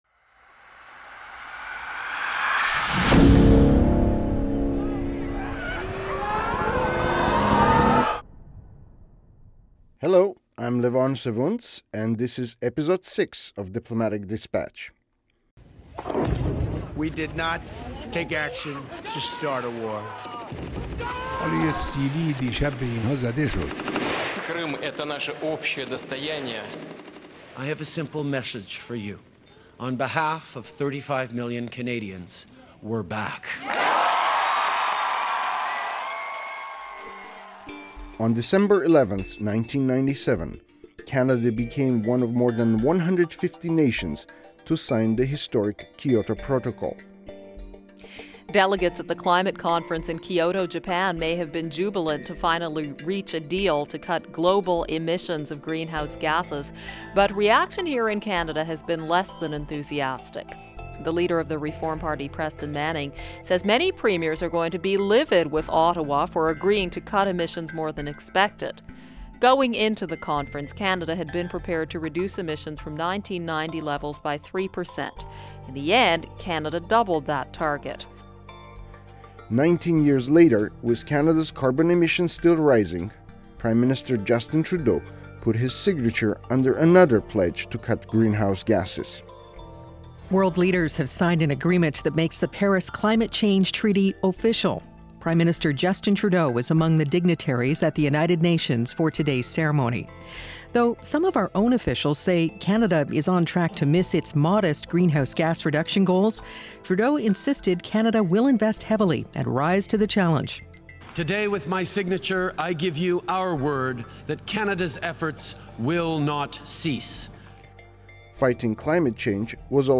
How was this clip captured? He spoke to Diplomatic Dispatch about the challenges facing Canada’s foreign policy in the age of climate crisis at the Summit on Canada’s Global Leadership in Ottawa on Nov. 28, 2019.